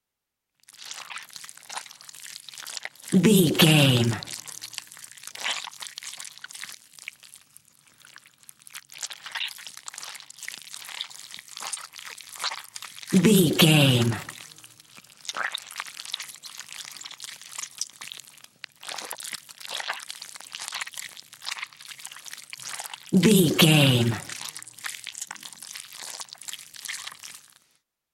Creature eating flesh juicy chew slow
Sound Effects
scary
disturbing
horror